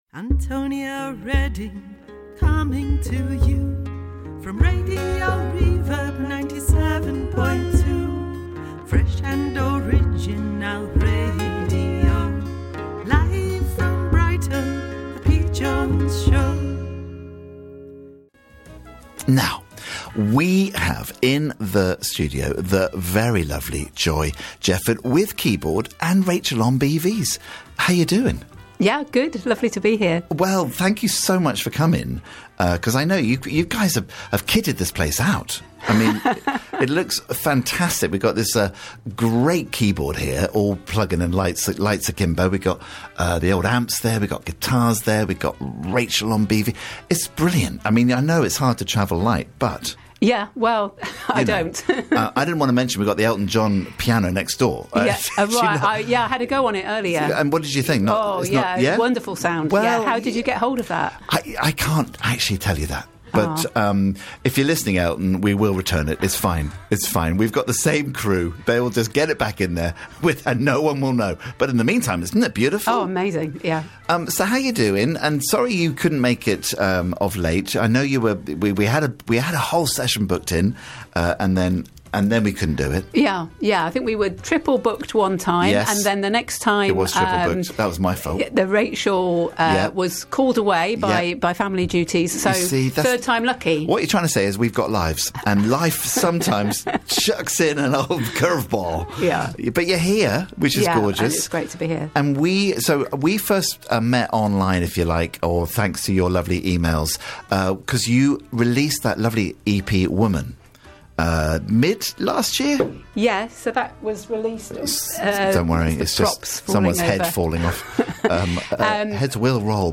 RadioReverb / Live session